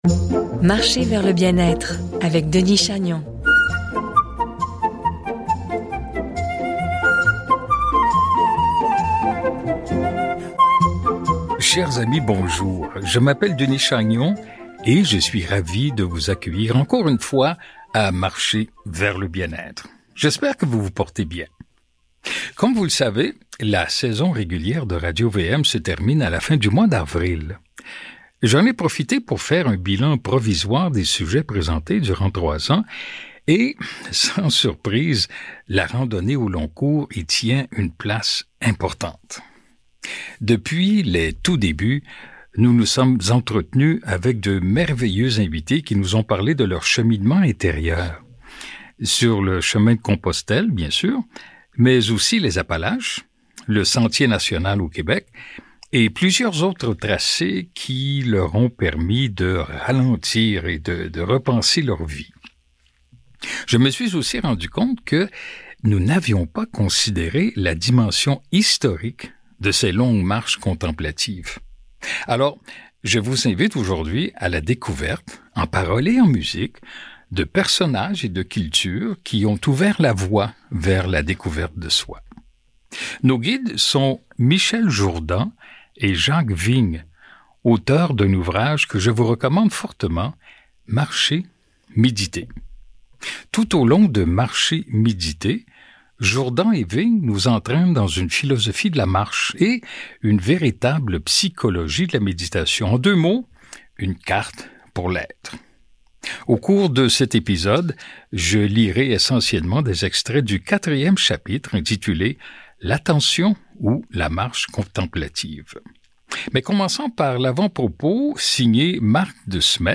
Il est grand temps d’aller à la découverte, en paroles et en musique, de personnages et de cultures qui ont depuis très longtemps ouvert la voie de la recherche du soi, par la marche.